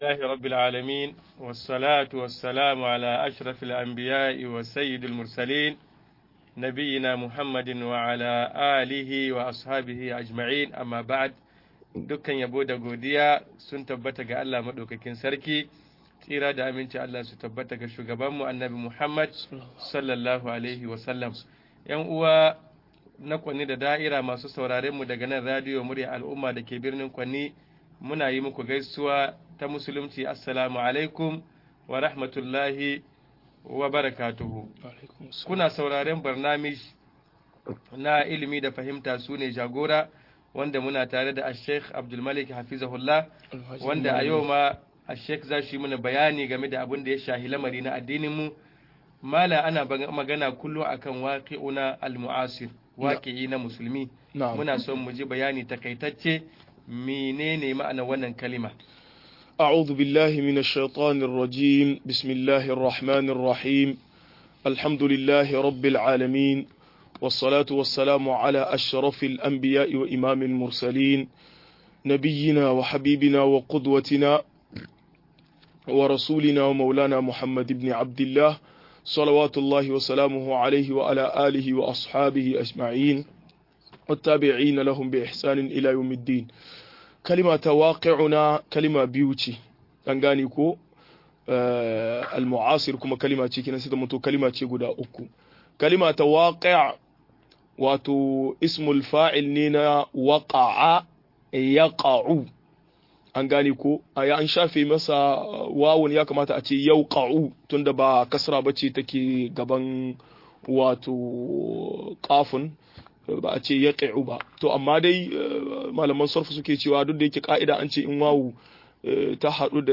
Halin musulamai a duniyar yau-01 - MUHADARA